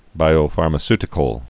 (bīō-färmə-stĭ-kəl)